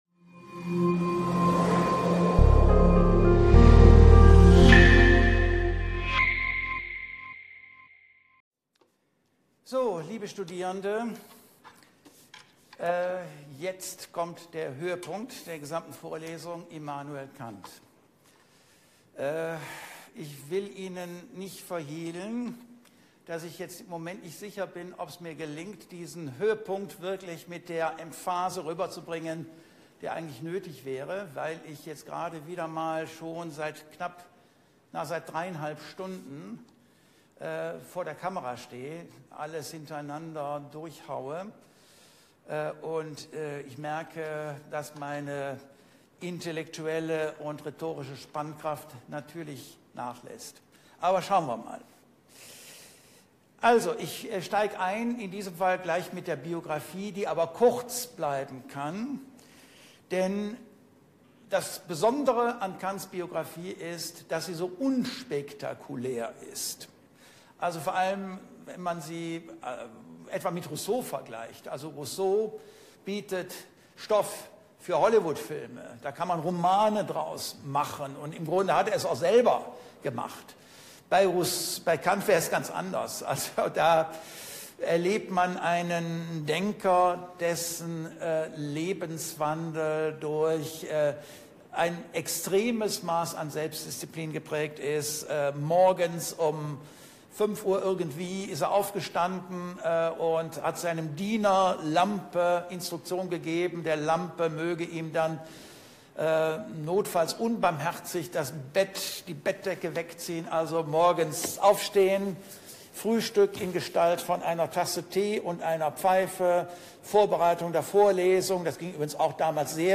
Die Vorlesung gibt exemplarische Einblicke in die Entwicklung des politischen Denkens von den antiken „Sophisten“ des 5. vorchristlichen Jahrhunderts bis in die europäische Aufklärung des 18. Jahrhunderts.